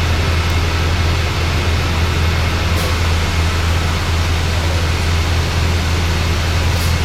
Add operating sounds to large and chungus turbines
chungusTurbine.ogg